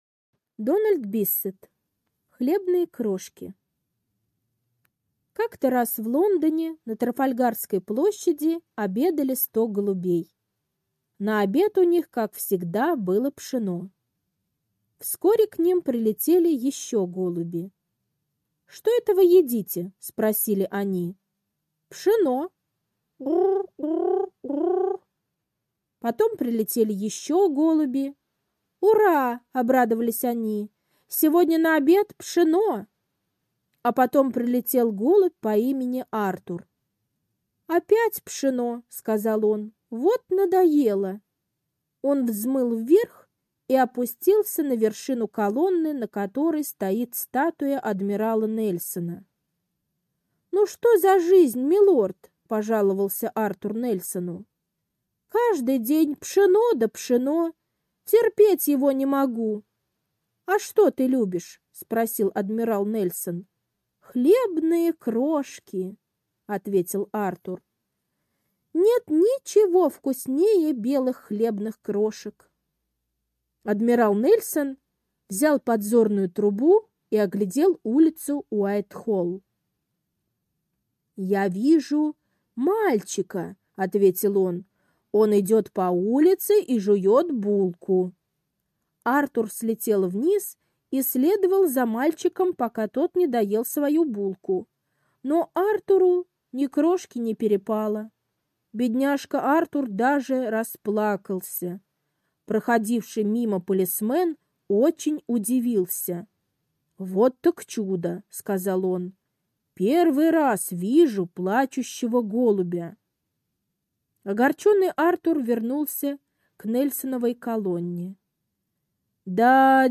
Аудиосказка «Хлебные крошки»